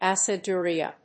/ˌæsɪˈdʊriʌ(米国英語), ˌæsɪˈdʊri:ʌ(英国英語)/